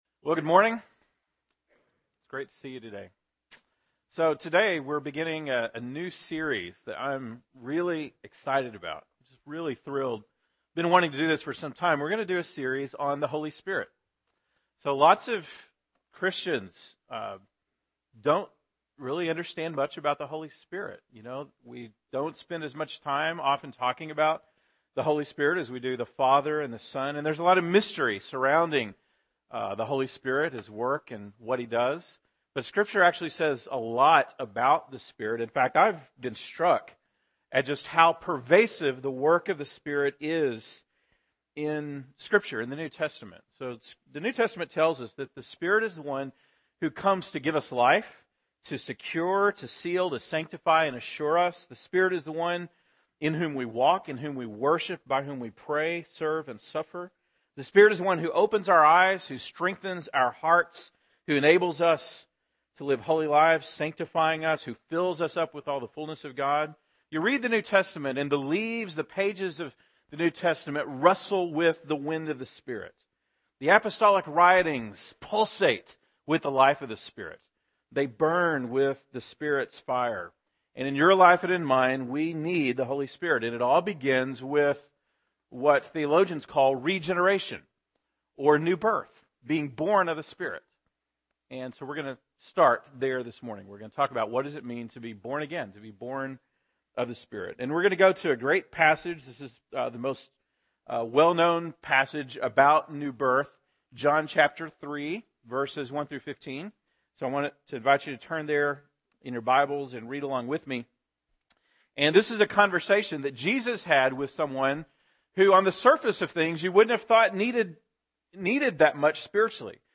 October 13, 2013 (Sunday Morning)